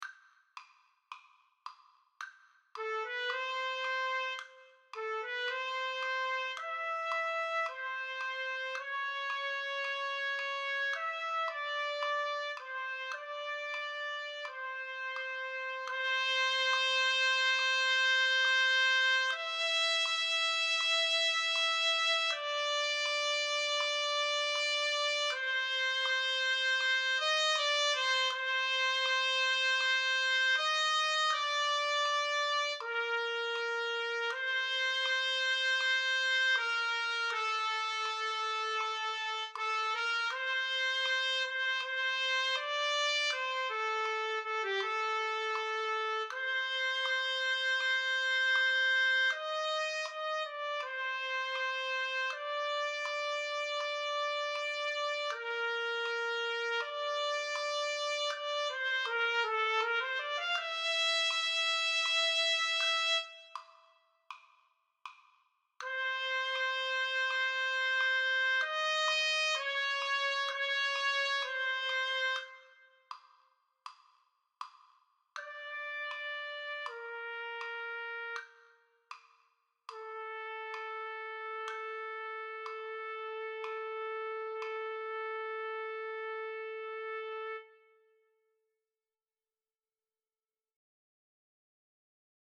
Bari1
Silence on the files indicate a rest for your part.